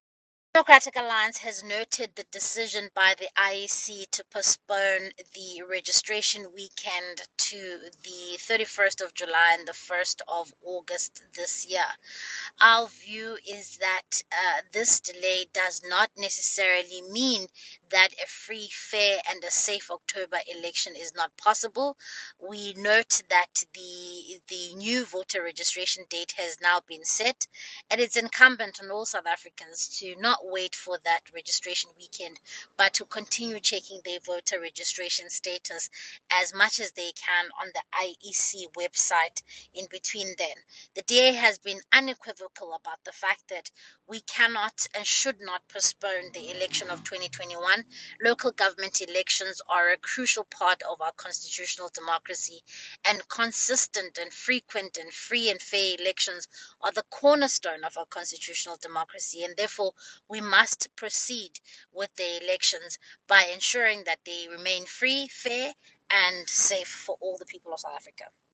Issued by Siviwe Gwarube MP – DA National Spokesperson
soundbite by Siviwe Gwarube MP.